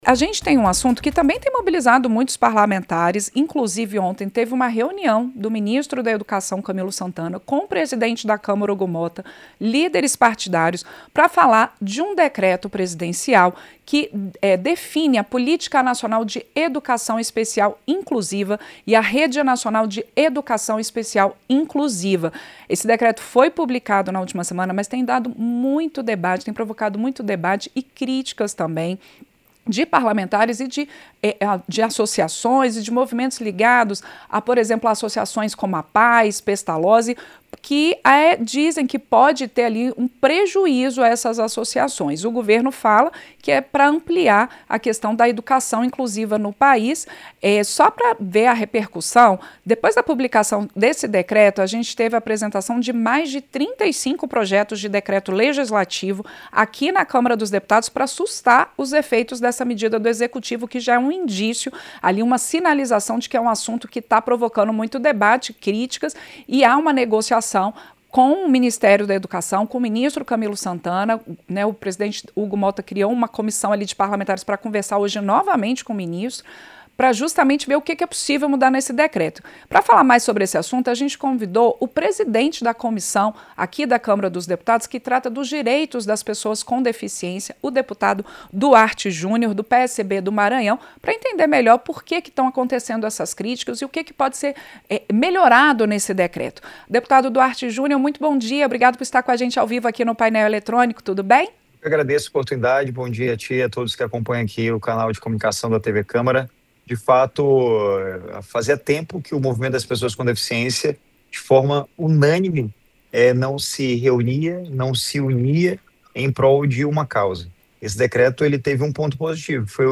Entrevista - Dep. Duarte Jr. (PSB-MA)